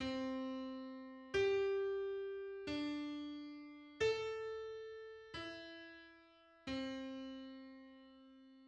So in that sequence, the second C is sharper than the first C by a syntonic comma
If a line of music follows that sequence, and if each of the intervals between adjacent notes is justly tuned, then every time the sequence is followed, the pitch of the piece rises by a syntonic comma (about a fifth of a semitone).
Comma_pump_on_C.mid.mp3